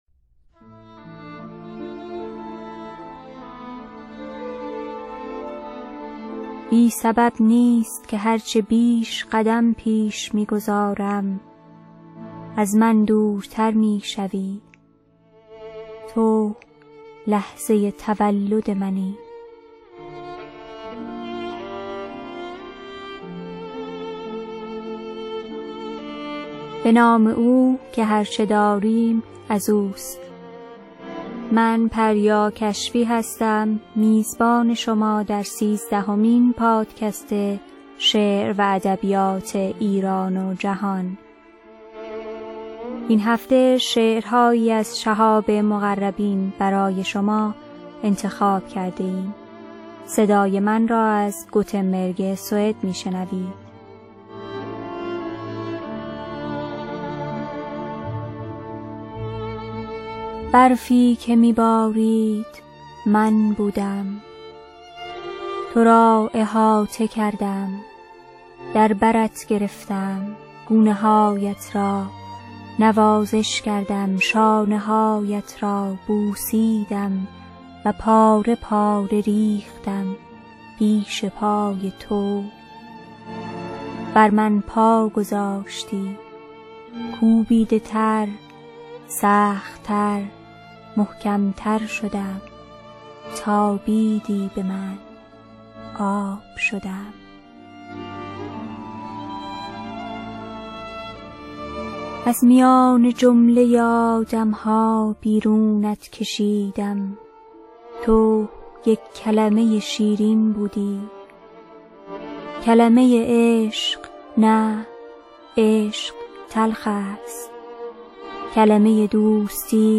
در این پادکست که هر دو هفته یکبار مهمان آن خواهید بود اشعاری از شاعران ایران و جهان برای شما دکلمه می شود.